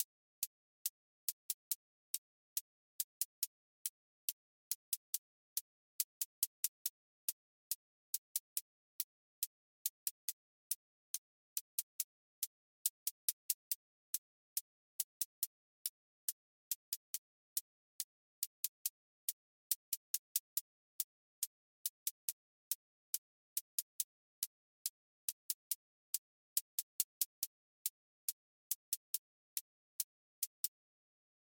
Trap 808 tension with clipped hats